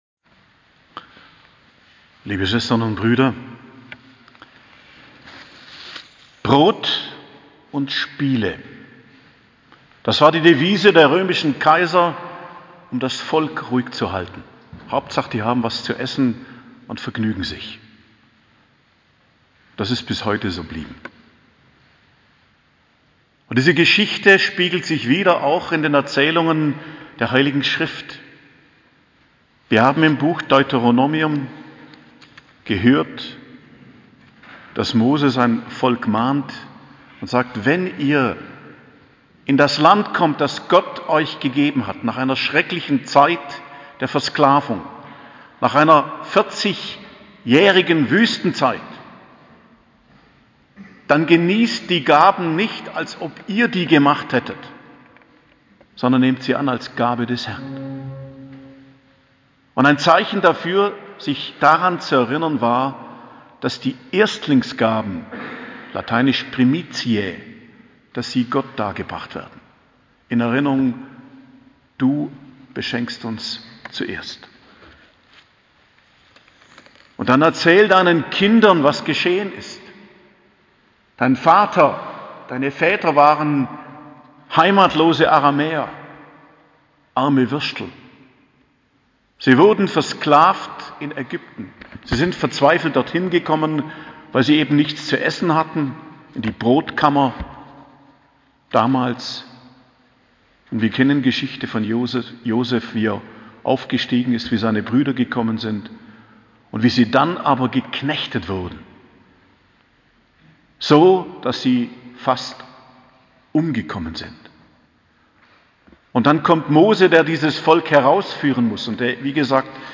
Predigt zum Ersten Fastensonntag, 6.03.2022 ~ Geistliches Zentrum Kloster Heiligkreuztal Podcast